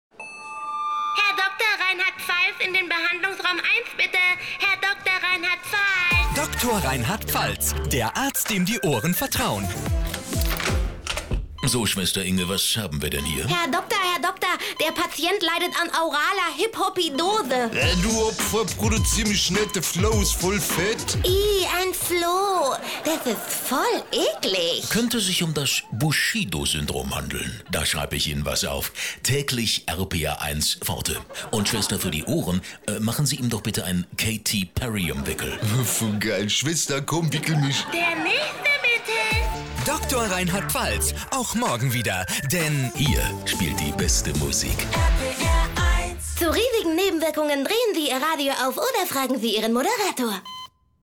hell, fein, zart, markant, sehr variabel
Station Voice / Comedy
Comedy